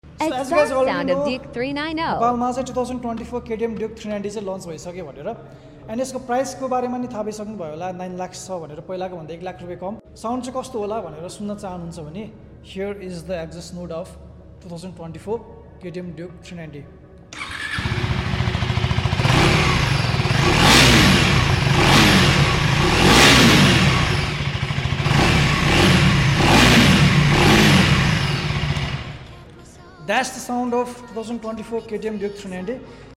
Exhaust sound of newly launched sound effects free download
Exhaust sound of newly launched 2024 ktm Duke 390 in Nepal.